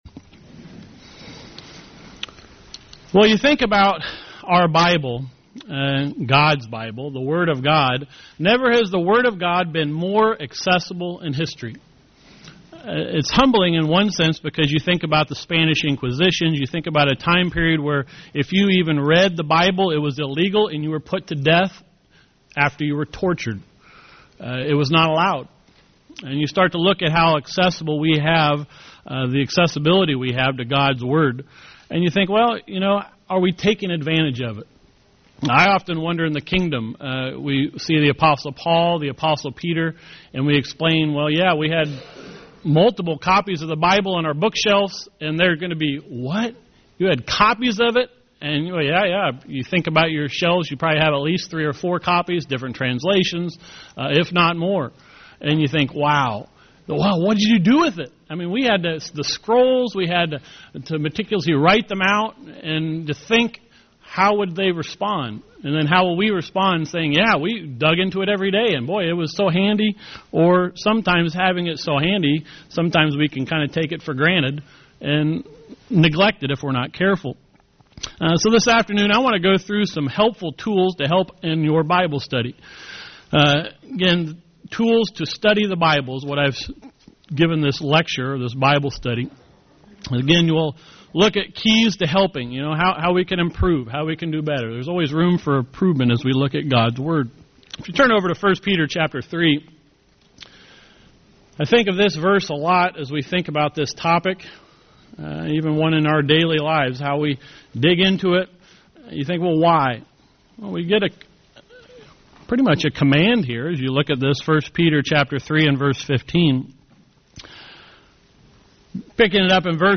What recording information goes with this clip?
Given in Northwest Indiana Elkhart, IN